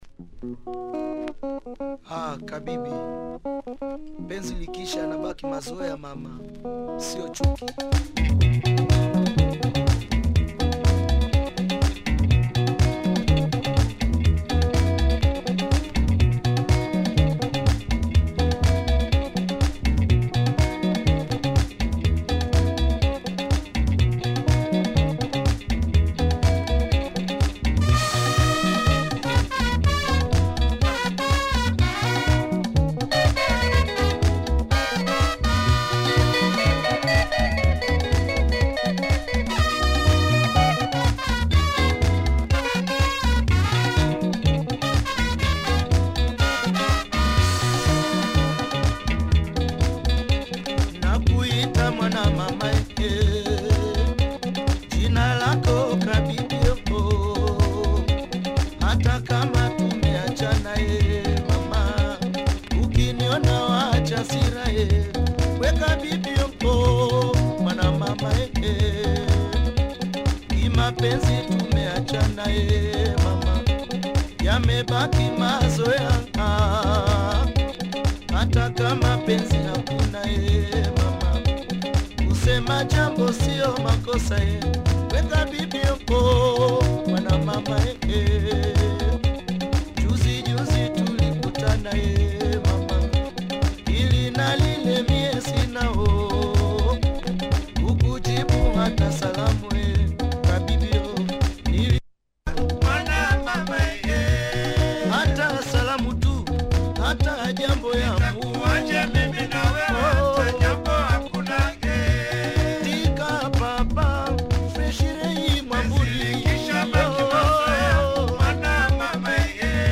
Poppy vibe